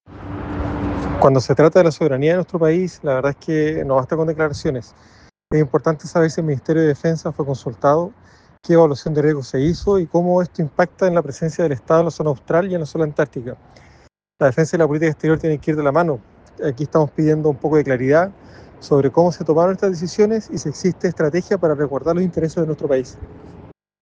El diputado Bassa, integrante de la Comisión de Defensa, advirtió que “cuando se trata de soberanía, no basta con declaraciones. Es importante saber si Defensa fue consultado, qué evaluación de riesgo se hizo y cómo esto impacta en la presencia del Estado en la zona austral y antártica”.
jaime-bassa.mp3